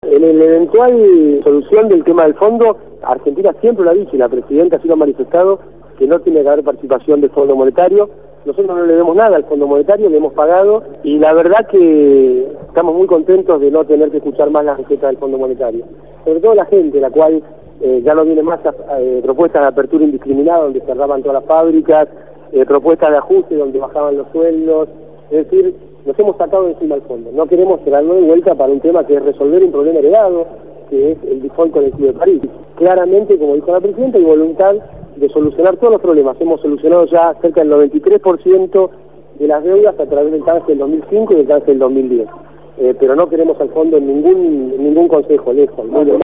Amado Boudou, Ministro de Economía de la Nación, fue entrevistado